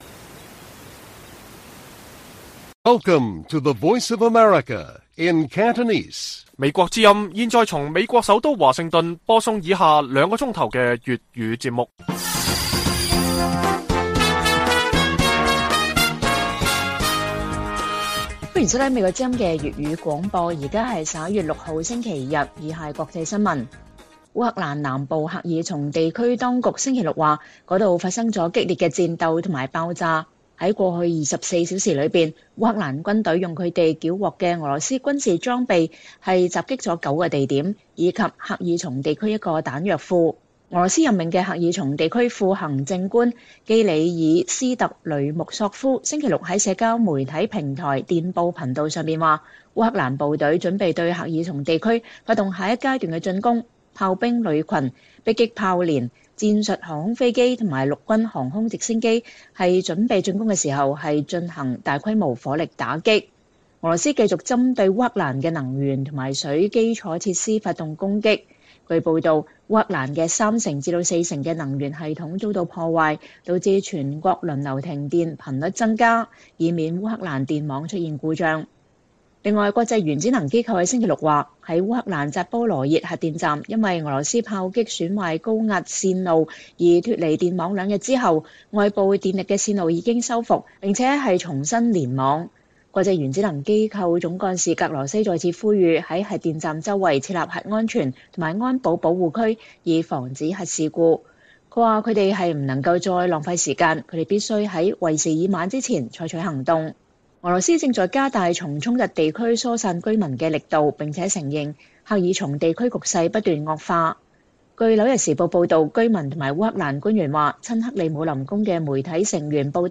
粵語新聞 晚上9-10點: 激烈的戰鬥威脅著烏克蘭的能源和供水基礎設施